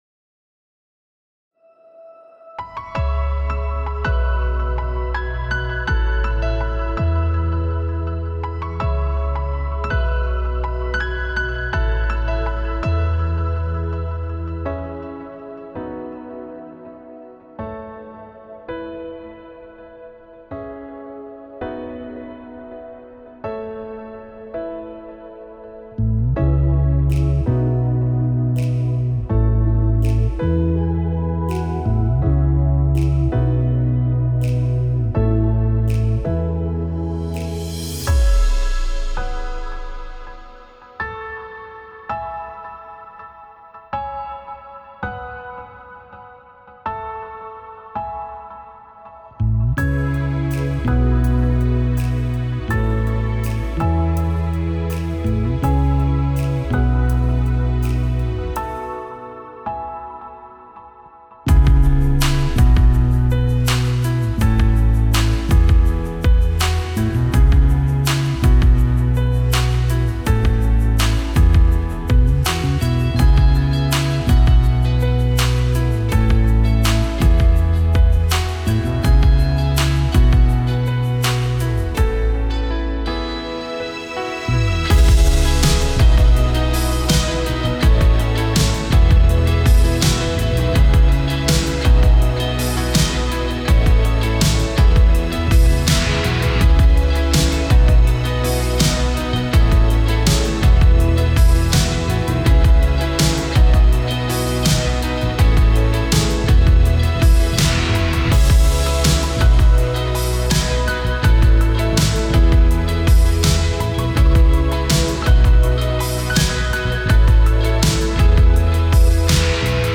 Key: E BPM: 82 Time sig: 4/4 Duration:  Size: 7.8MB
Contemporary Pop Worship